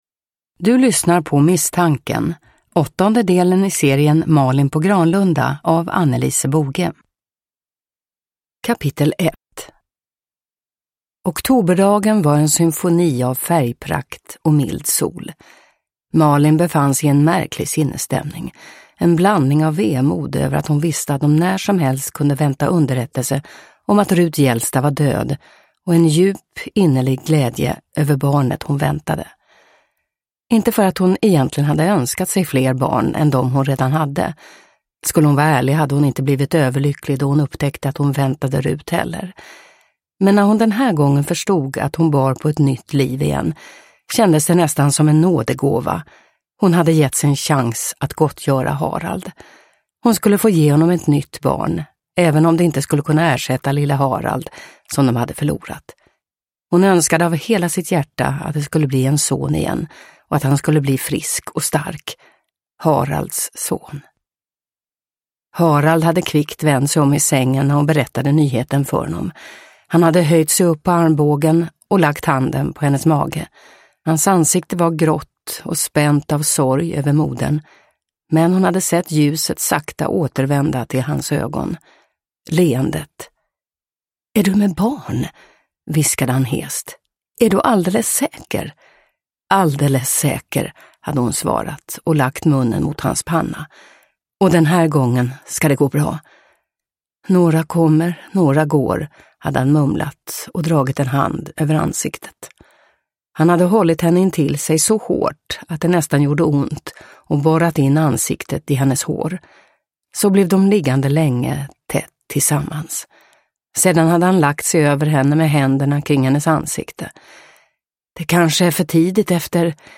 Misstanken – Ljudbok – Laddas ner